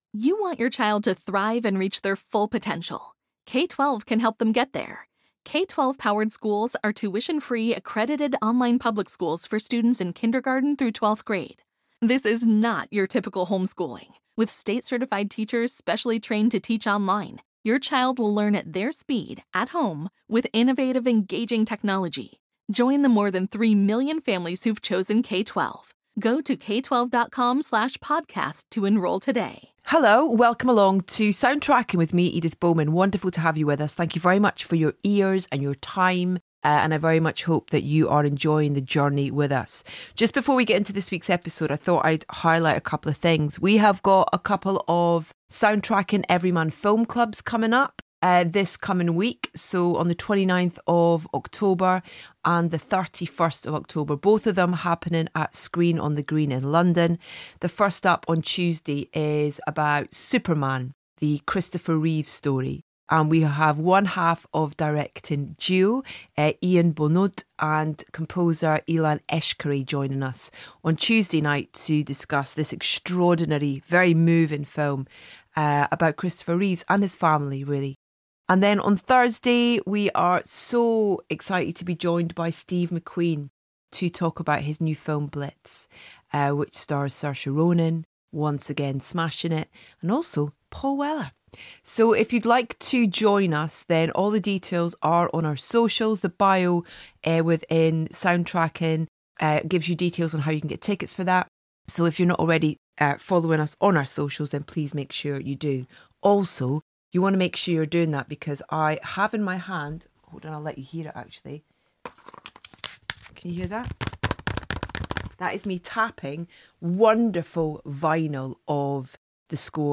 Our latest guests on Soundtracking are Cillian Murphy and Tim Mielants, who join Edith to discuss their new film, Small Things Like These.